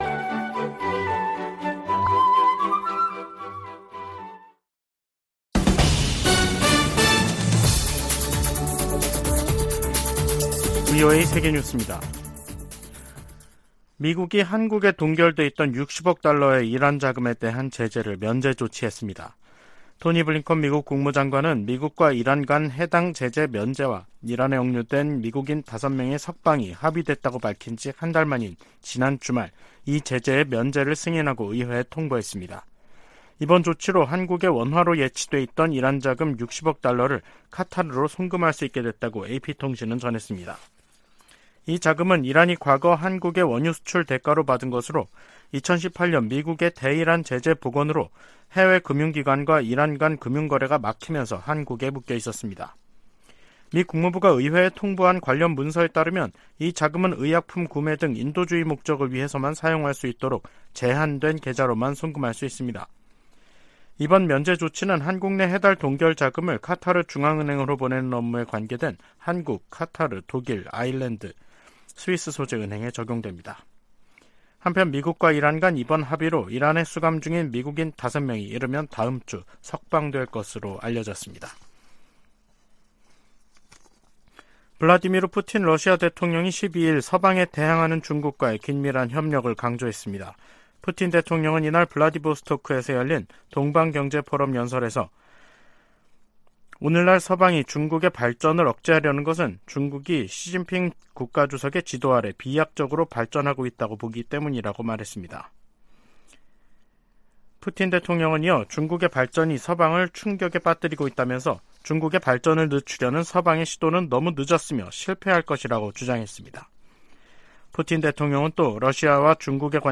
VOA 한국어 간판 뉴스 프로그램 '뉴스 투데이', 2023년 9월 12일 3부 방송입니다. 김정은 북한 국무위원장이 러시아 방문에 군부 실세들을 대동하고 있는 것으로 알려졌습니다. 미국 정부는 북러 정상회담을 면밀히 주시한다고 밝히고 북한이 러시아에 무기를 제공하지 않겠다고 한 약속을 지킬 것을 촉구했습니다. 북한 풍계리에서 추가 핵실험을 지원할 수 있는 활동 징후가 계속 포착되고 있다고 국제원자력기구(IAEA) 사무총장이 밝혔습니다.